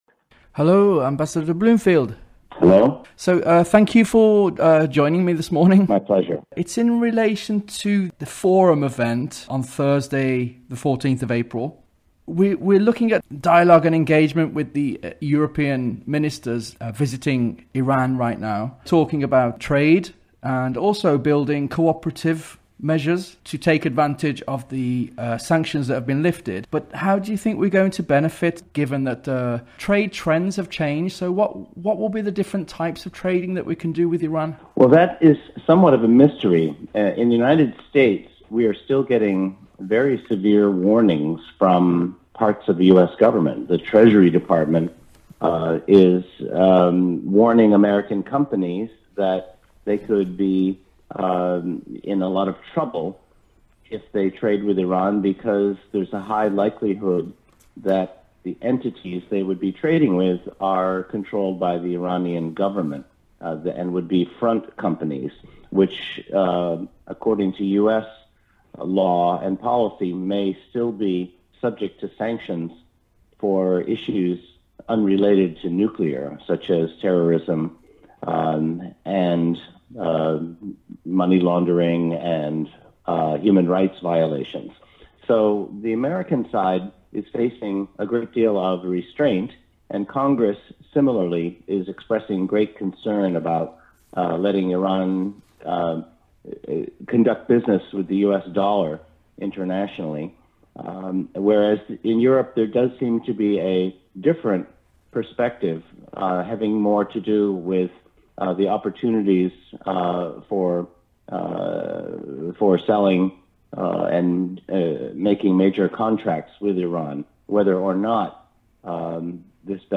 Interview on Iran